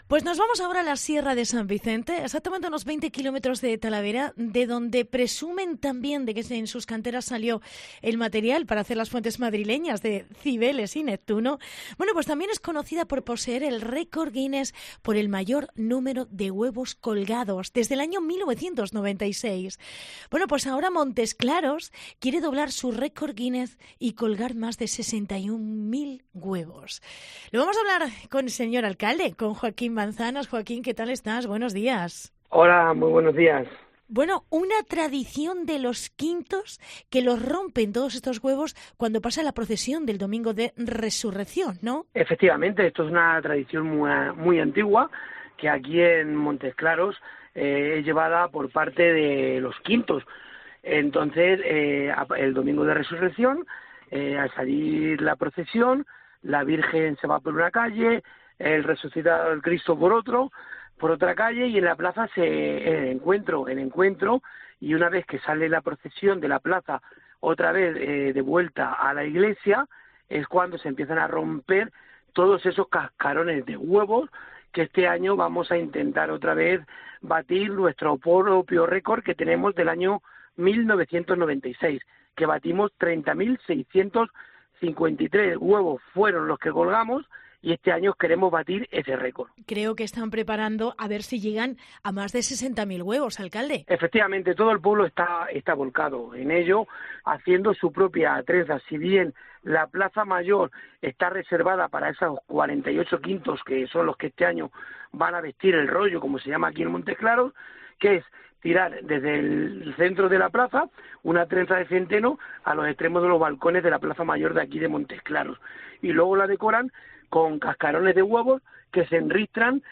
Entrevista Joaquín Manzanas. Alcalde de Montesclaros